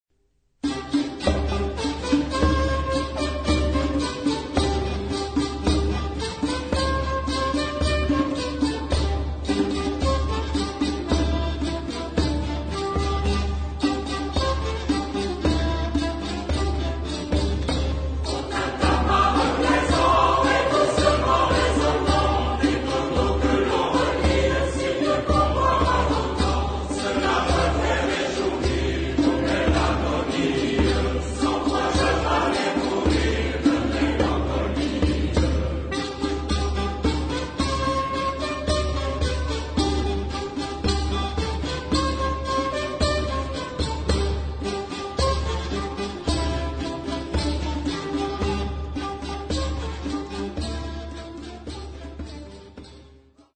Genre-Style-Form: Folk music ; Secular
Type of Choir: SATB  (4 mixed voices )
Tonality: C major
Origin: Normandy (F)
Musicological Sources: Air traditionnel